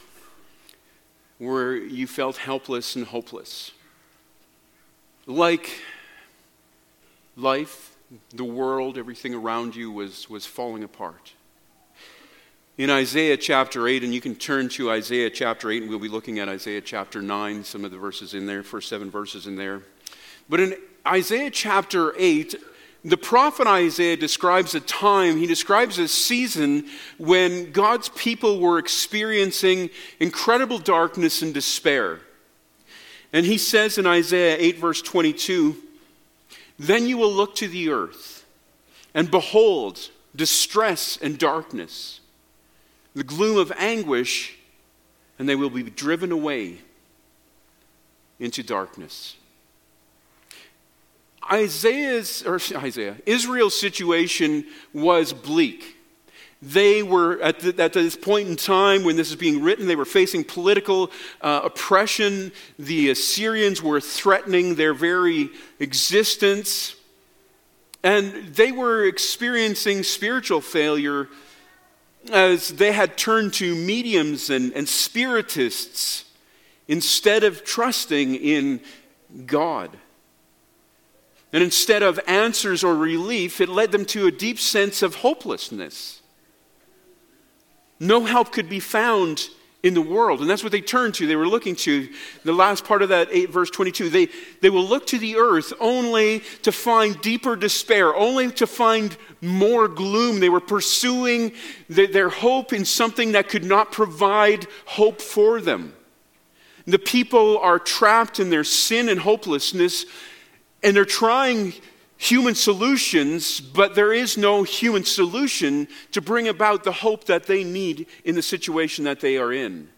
Passage: Isaiah 9:1-7 Service Type: Sunday Morning Topics